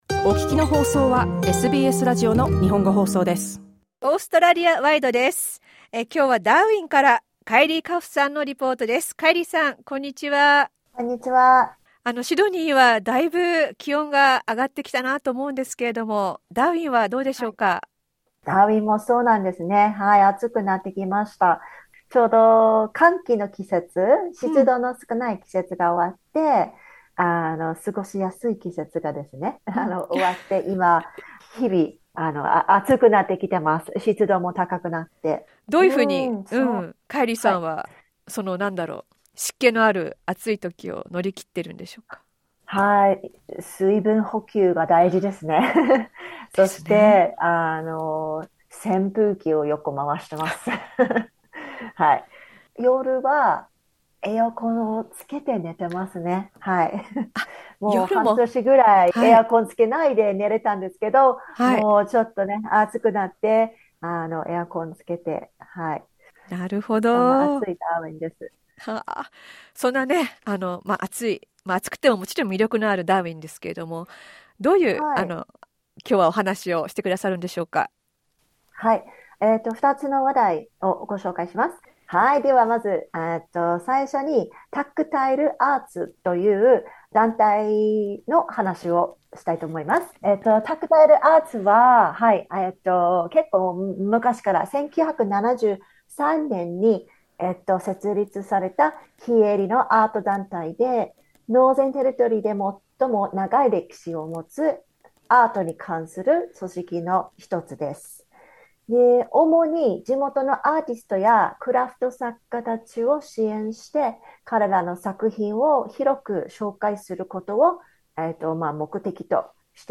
SBSの日本語放送